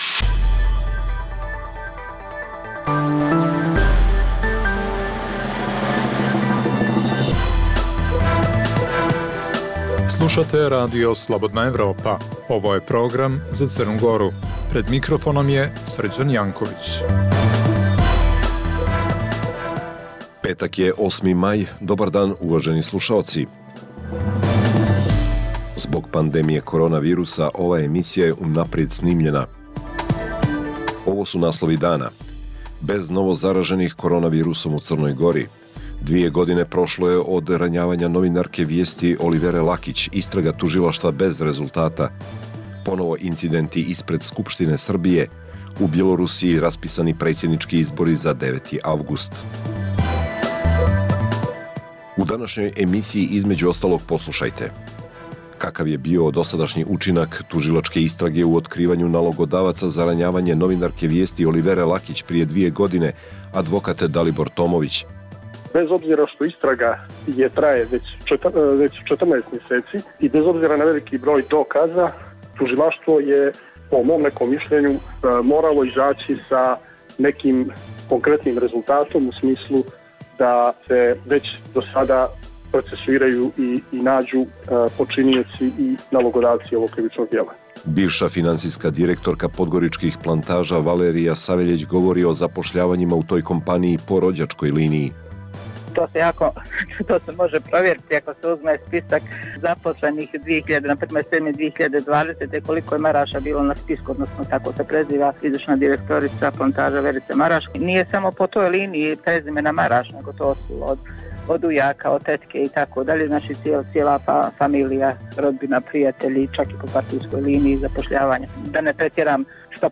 Emisija namijenjena slušaocima u Crnoj Gori. Sadrži lokalne, regionalne i vijesti iz svijeta i tematske priloge o aktuelnim dešavanjima. Zbog pooštrenih mjera kretanja u cilju sprečavanja zaraze korona virusom, ovaj program je unaprijed snimljen.